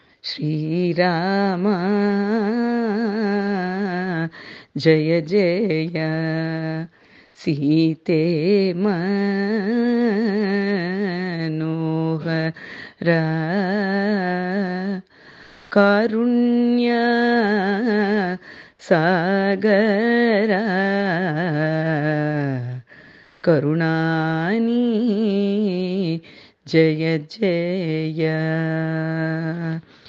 Harikatha combines storytelling, poetry, and Carnatic music, bringing to life tales from epics like the Mahabharata and Ramayana and creating a cultural bridge across generations.